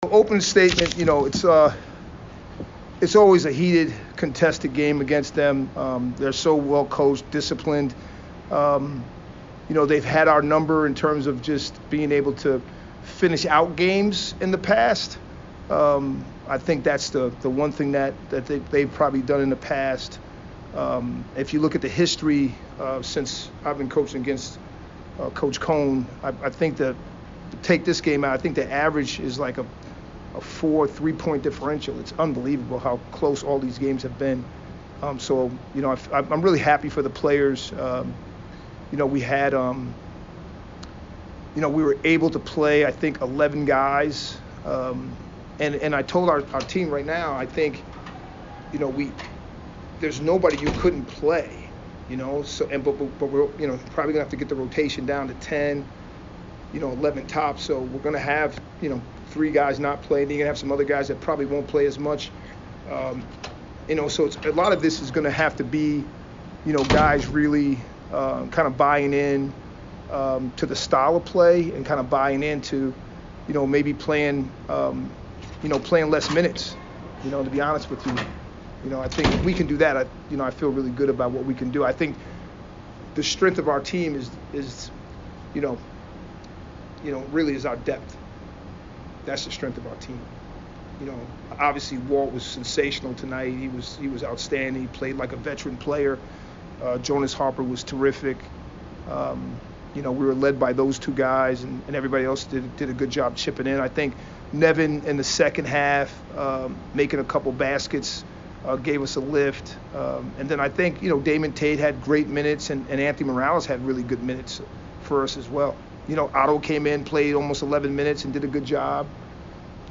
Men's Basketball / Northeastern Postgame Interview